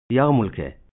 Jewish vocabulary pronunciation